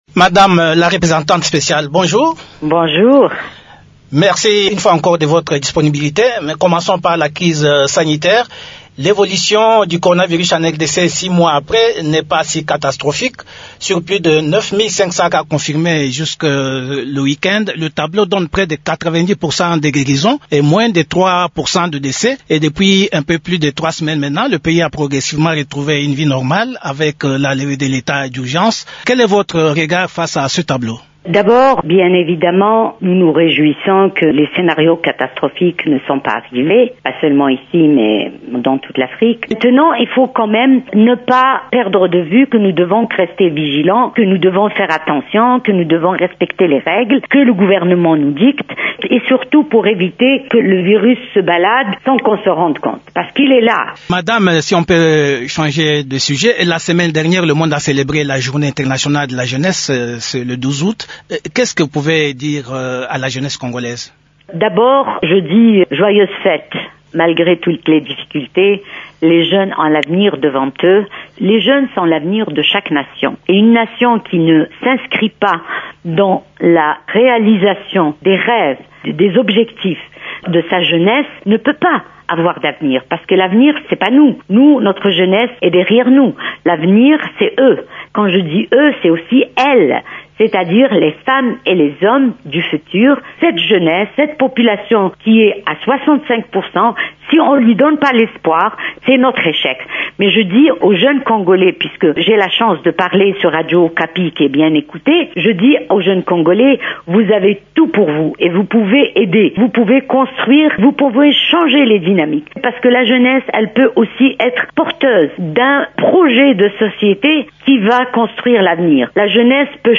Elle a abordé lundi 17 août, plusieurs sujets d’actualité nationale sur les ondes de la Radio Okapi.